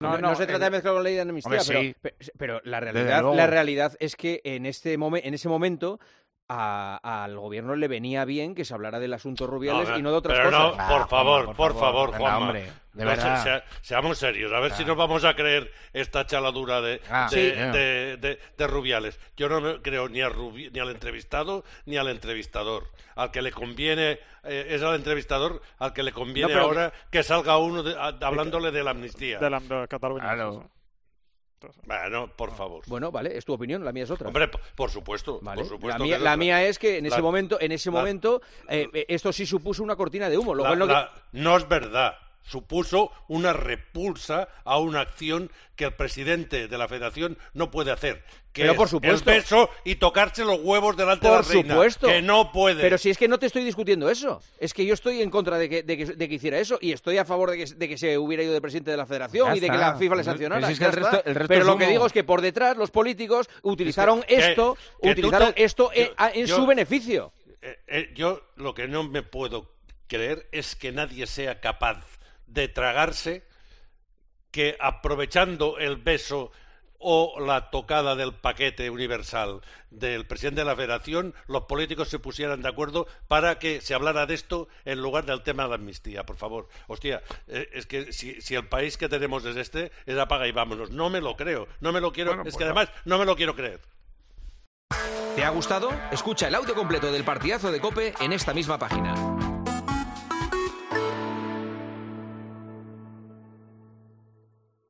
Esto mereció un debate en el programa de este miércoles.